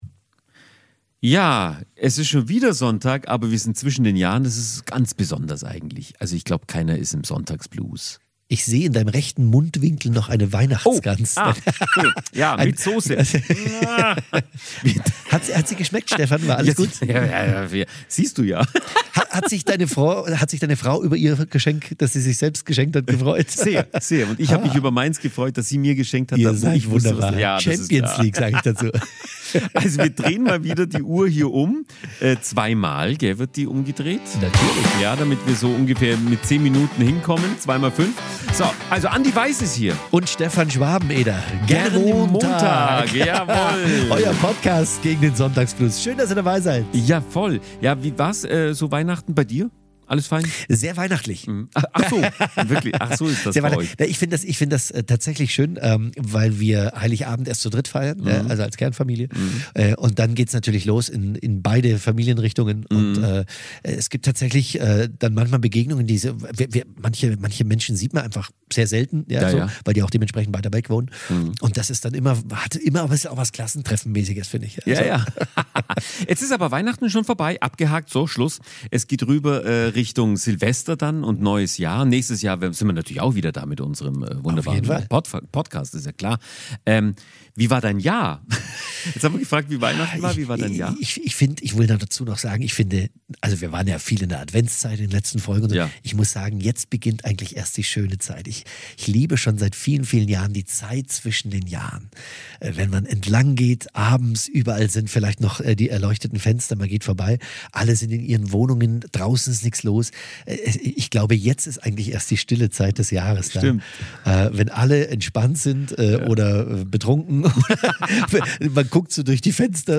Unsere "Montagsfreunde", die kreativen KI-Musiker, haben für euch einen exklusiven Song komponiert und werfen einen musikalischen Blick zurück.